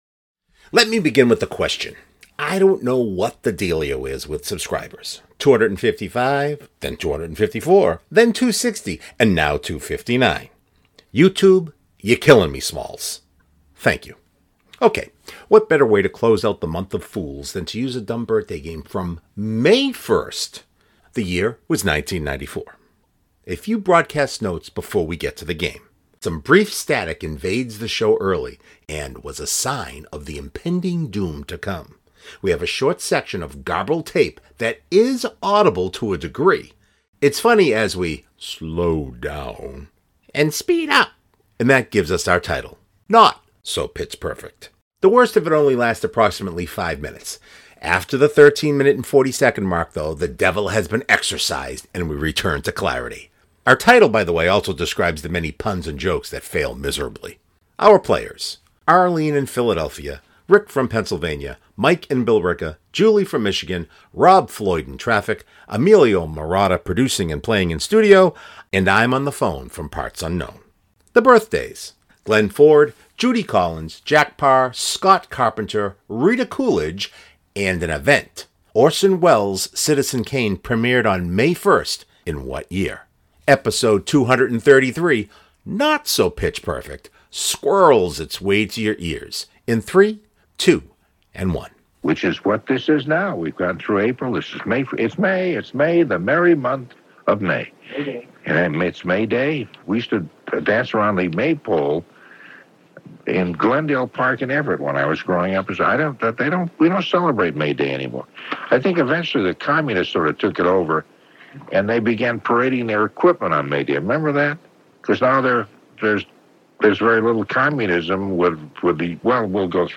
A few broadcast notes before we get to the game: Some brief static invades the show early and was a sign of the impending doom to come. We have a short section of garbled tape that IS audible to a degree.
After the 15.00 mark, the devil has been exorcised, and we return to clarity!